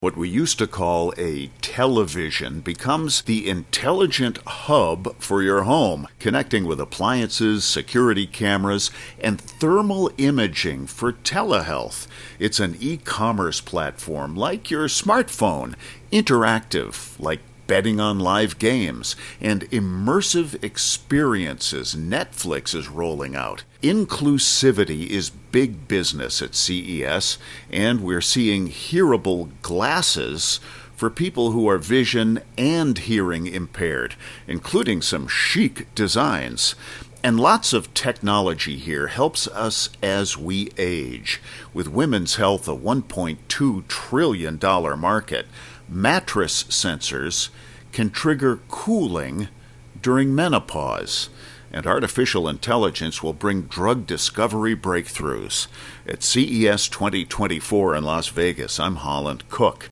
Help yourself to daily 60-second reports from CES2024, for air January 8-12.